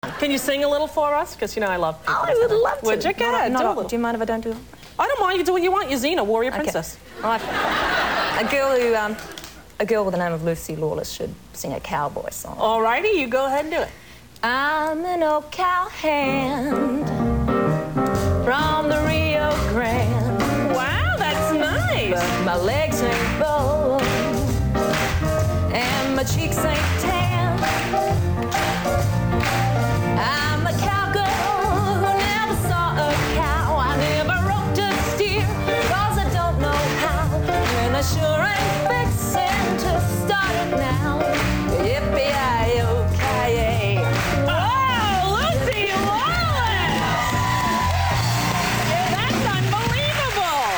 kovbojskou píseň